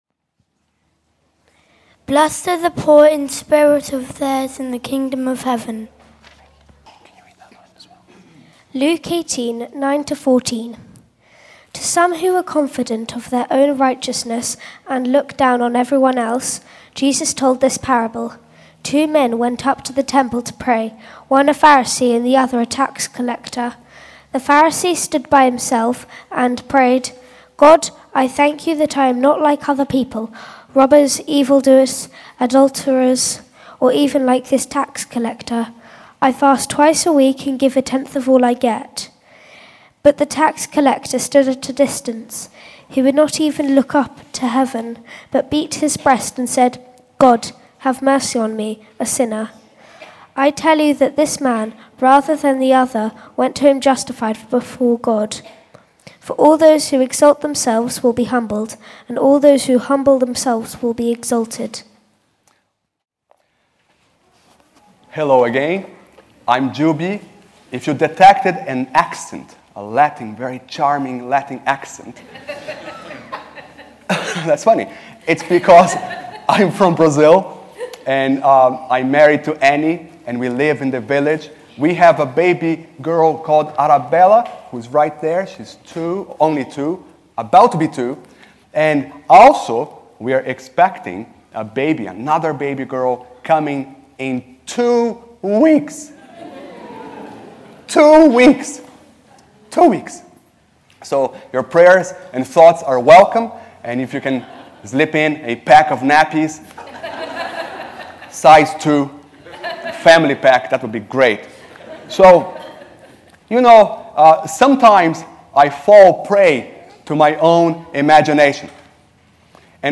Theme: Sermon